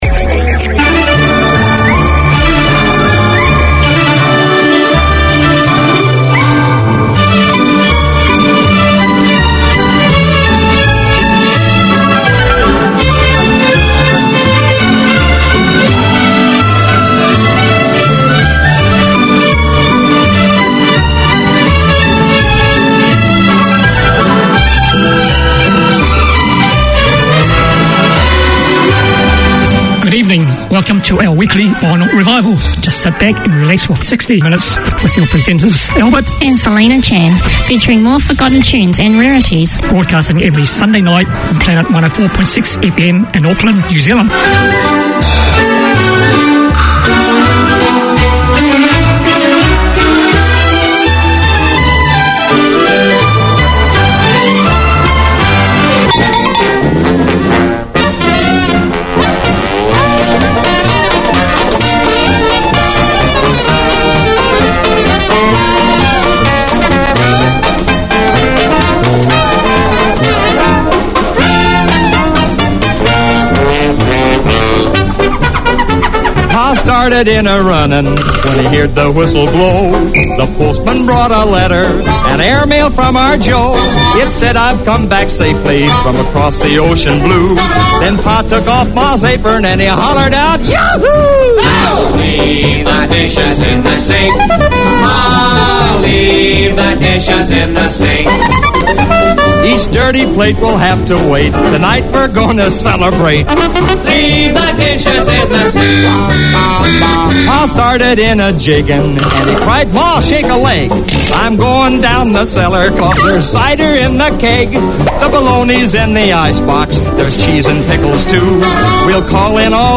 Listen for the best of the 30's,40's 50's,60's including hits and rarities.